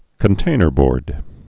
(kən-tānər-bôrd)